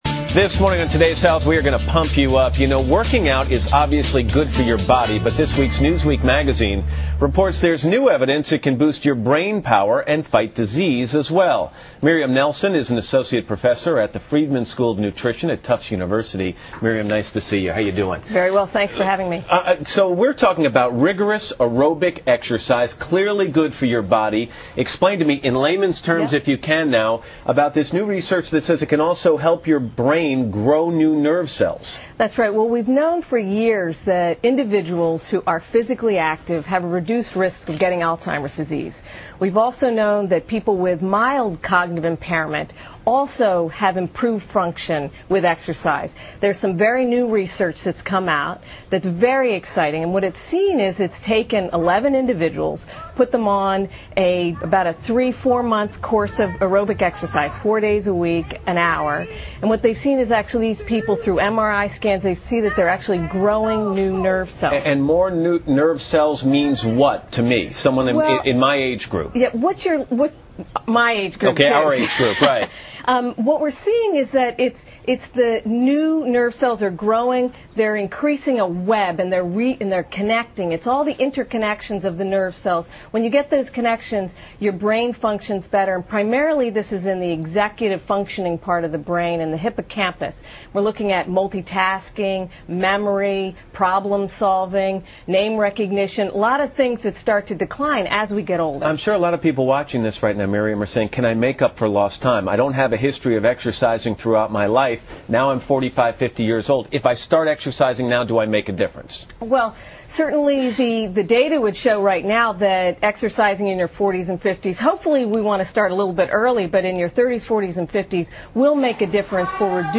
访谈录 Interview 2007-03-28&30, 我要变聪明 听力文件下载—在线英语听力室